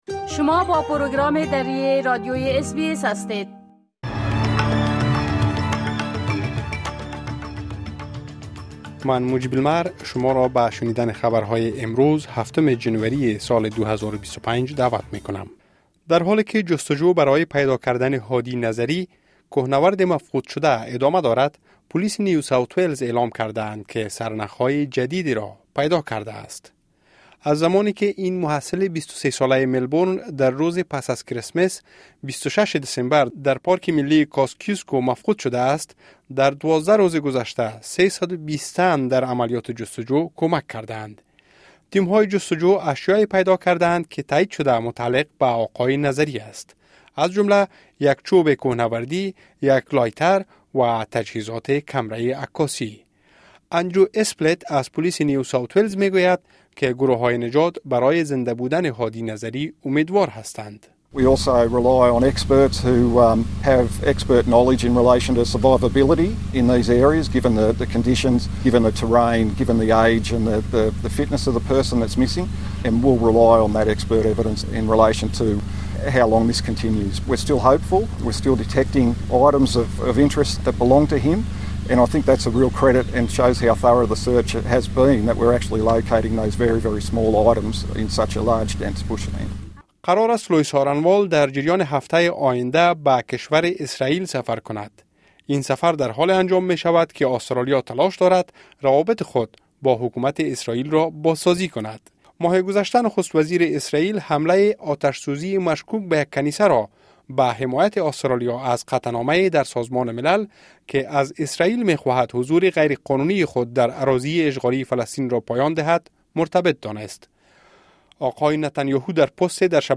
مهمترين اخبار روز از بخش درى راديوى اس بى اس|۷ جنوری